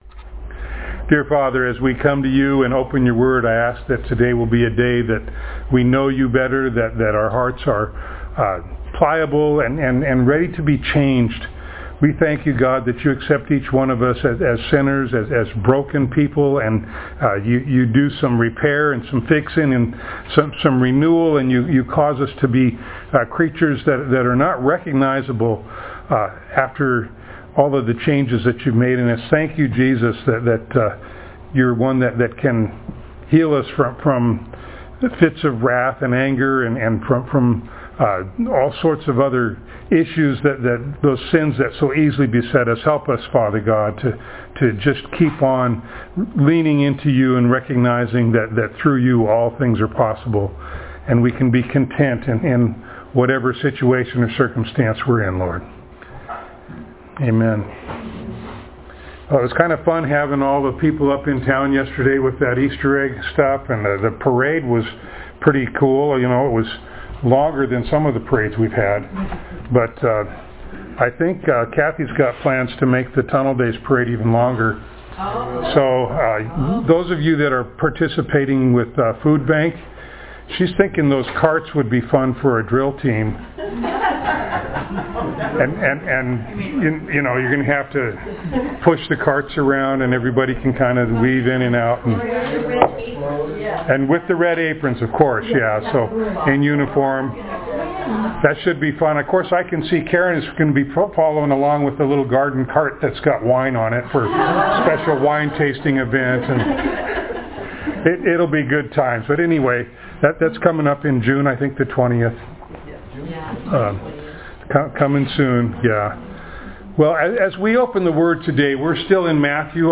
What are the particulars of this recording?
Matthew Passage: Matthew 15:1-20, Mark 7:1-23, Luke 23:39-43 Service Type: Sunday Morning